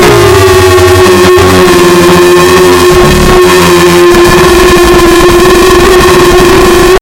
iphone alarms be like Download for iphone